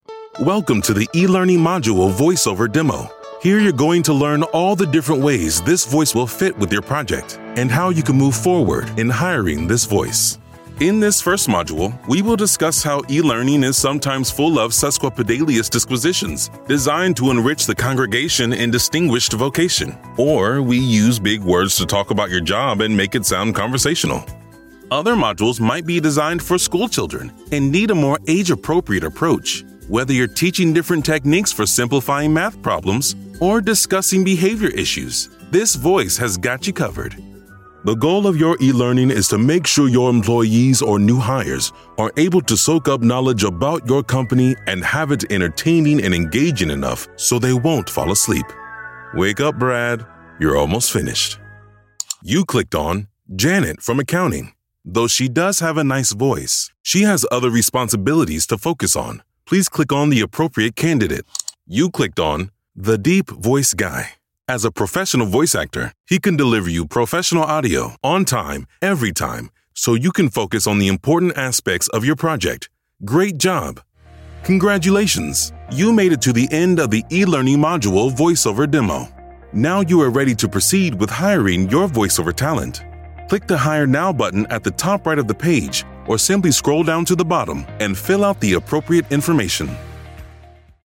Deep Voice, Powerful, Epic
eLearning